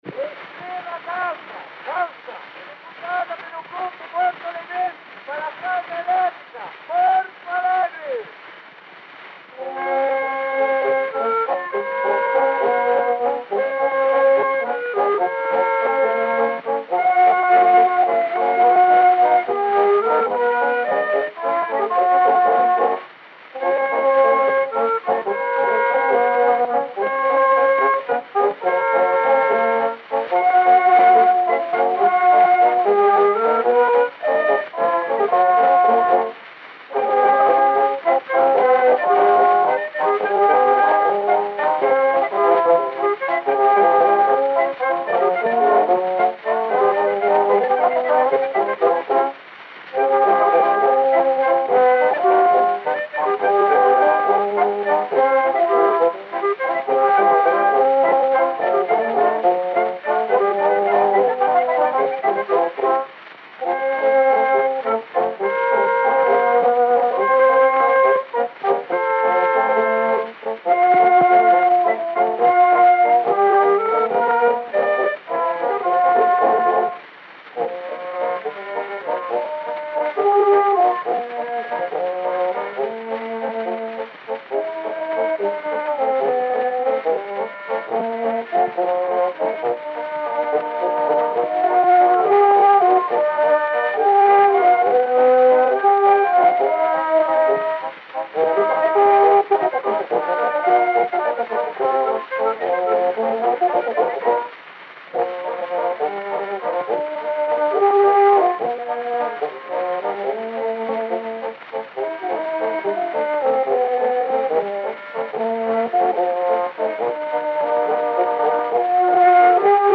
Disco de 78 rotações, também chamado "78 rpm", gravado em apenas um lado e com rótulo "tricolor".
A performance da música foi executada pelo "Grupo Porto Alegrense". O gênero musical foi descrito como "Valsa". O disco está rachado.